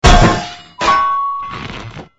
AA_drop_anvil.ogg